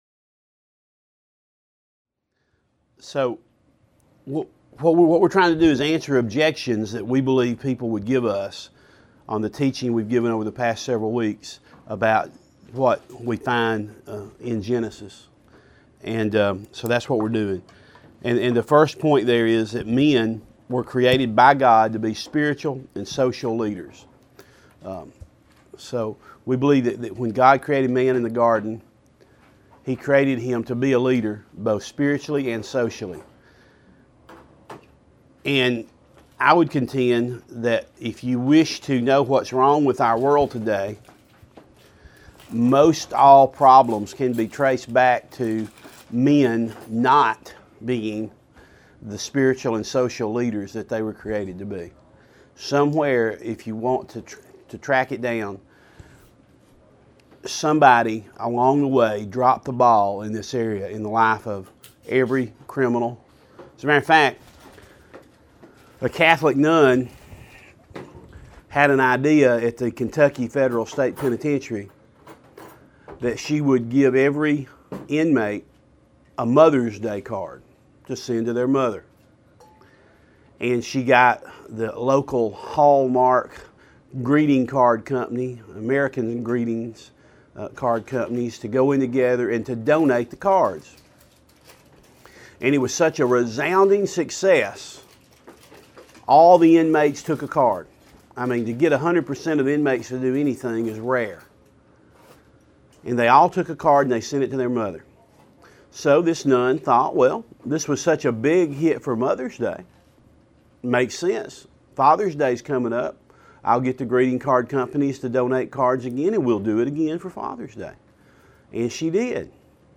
Each Tuesday morning a group of men sit down together to hear what the Bible has to say about authentic manhood.